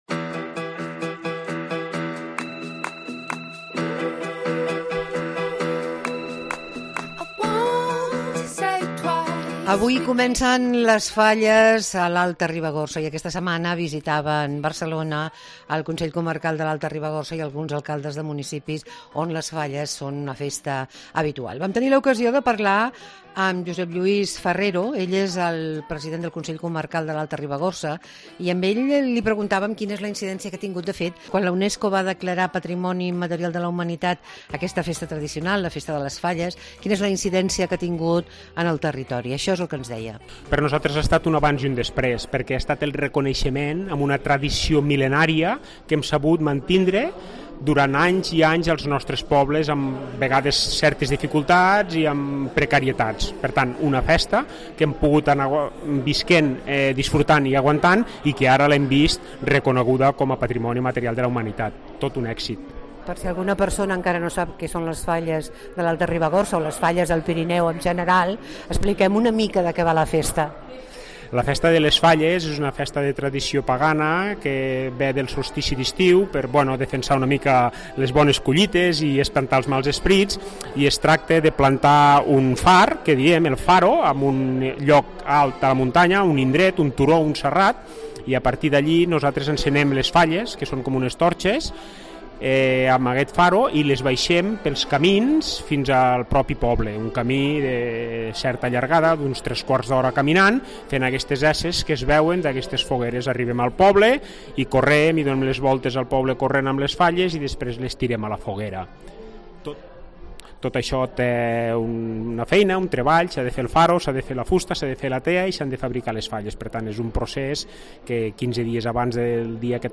Començen Les Falles a l'Alta Ribagorça, declarades patrimoni immaterial de la Humanitat per la UNESCO. Parlem amb Josep Lluis Farrero, president del Consell comarcal de l'Alta Ribagorça